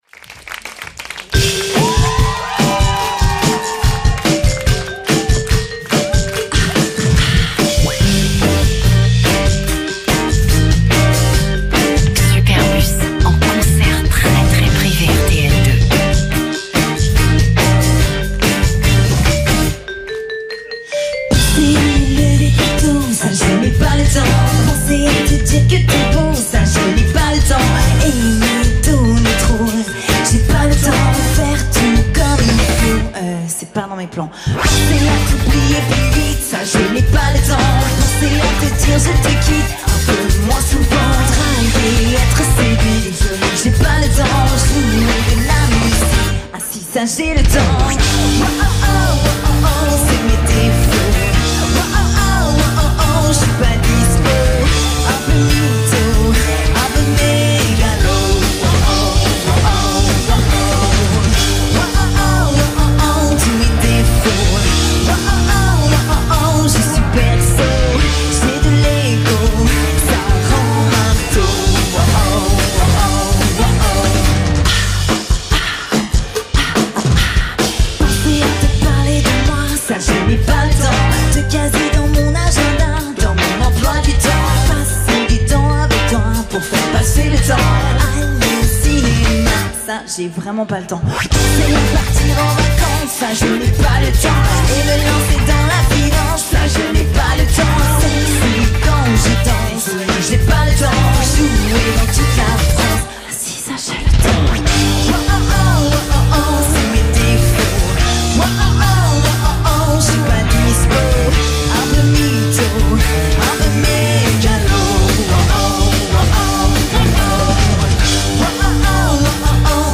French rock band